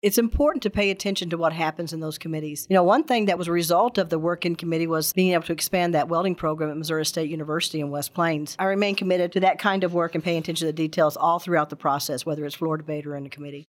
4. Senator Eslinger says she believes committees to be the backbone of legislative work.